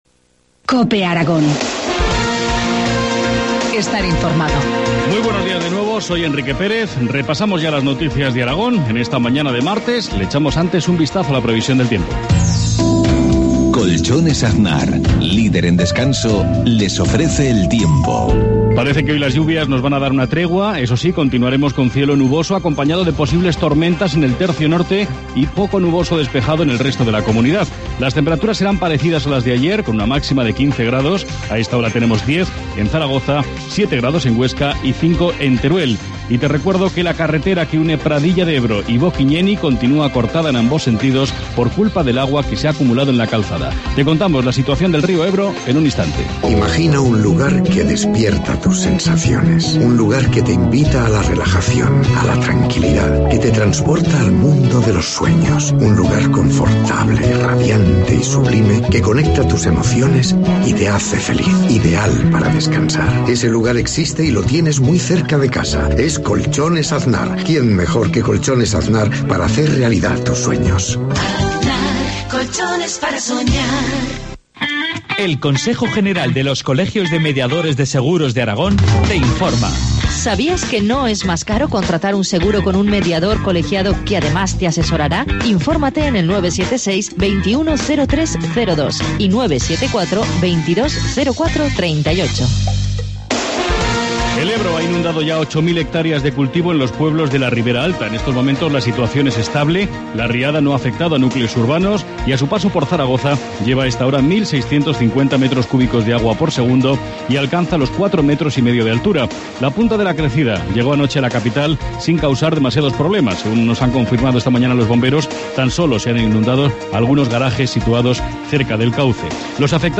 Informativo matinal, martes 2 de abril, 7.53 horas